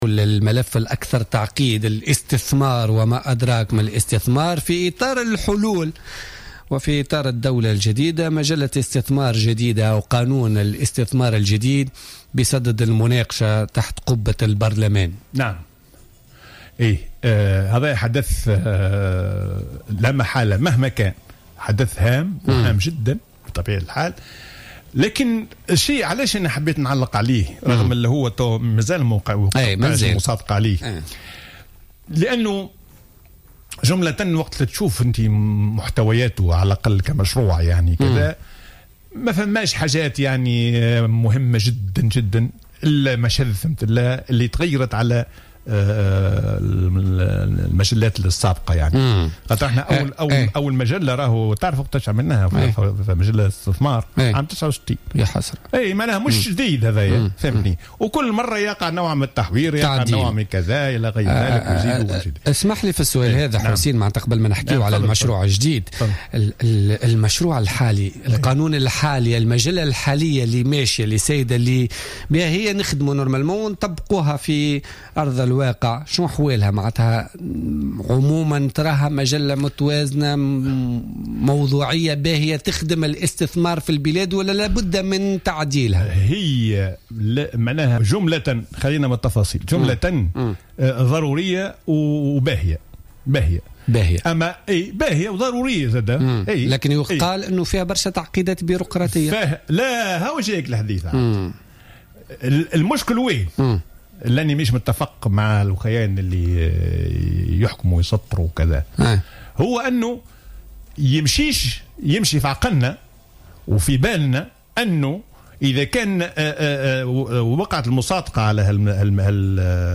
قال حسين الديماسي الخبير الاقتصادي ووزير المالية السابق في تصريح للجوهرة أف أم في برنامج بوليتكا لليوم الجمعة 26 فيفري 2016 إن ضعف البنية التحتية في البلاد واهتراءها يعرقل الاستثمار ويحول دون دفع الاقتصاد في البلاد.